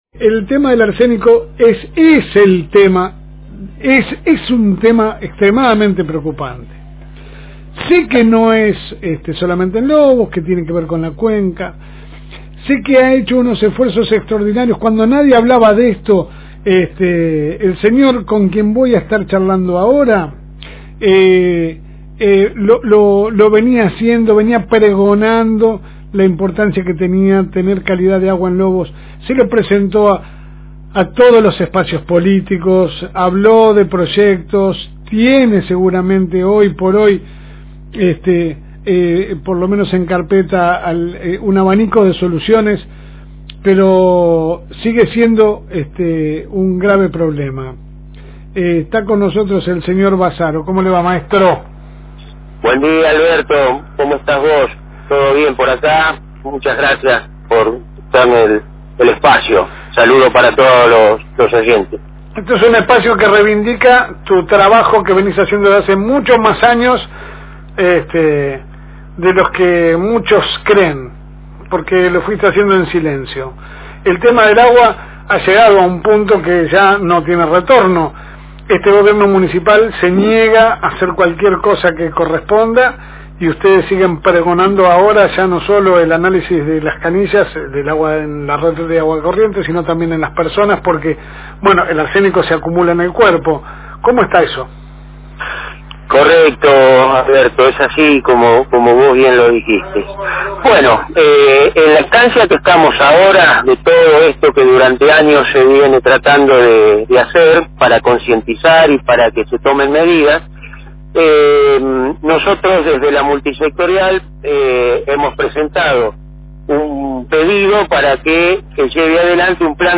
En comunicación telefónica charlamos